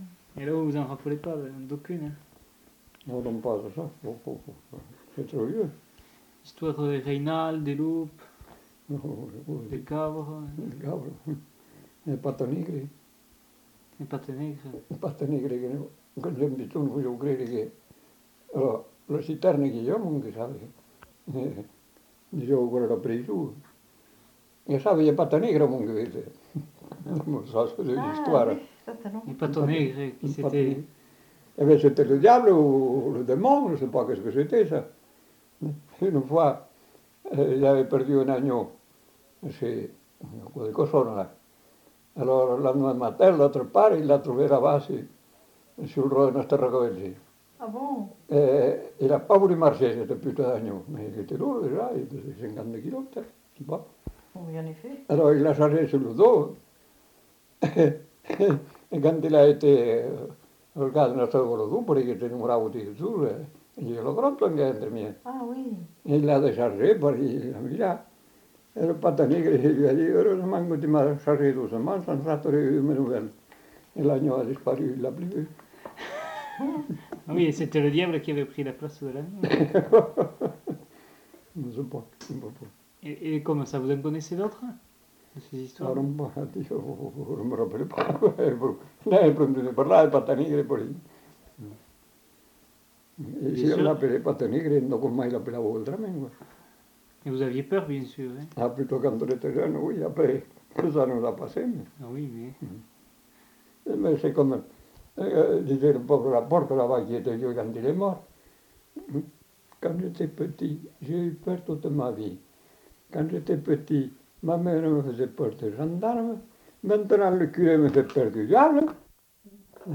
Lieu : Lacroix-Barrez
Genre : conte-légende-récit
Effectif : 1
Type de voix : voix d'homme
Production du son : parlé